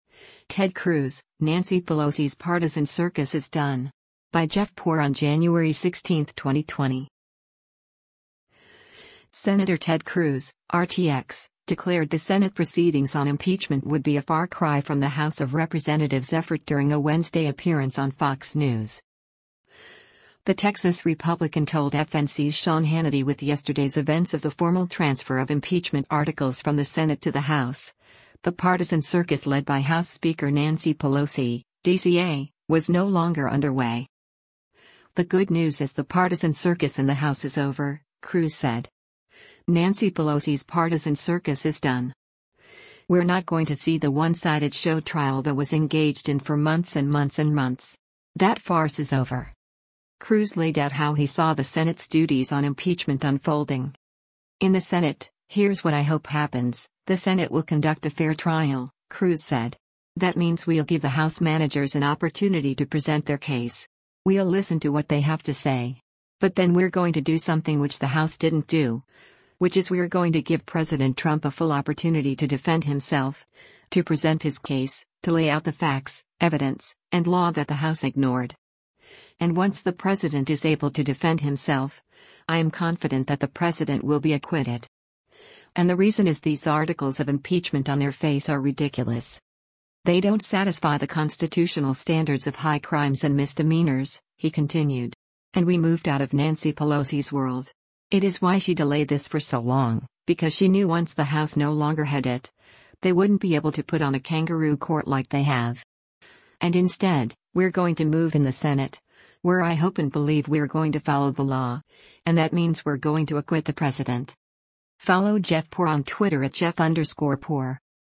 Senator Ted Cruz (R-TX) declared the Senate proceedings on impeachment would be a far cry from the House of Representatives effort during a Wednesday appearance on Fox News.